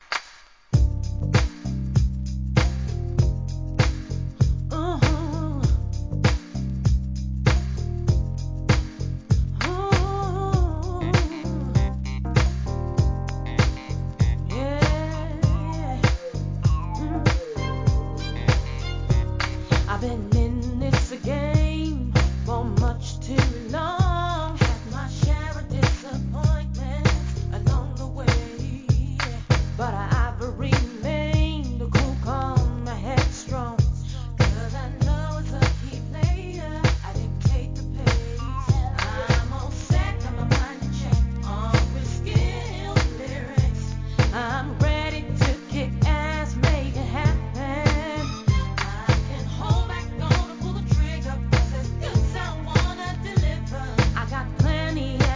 HIP HOP/R&B
ACOUSTIC VERSION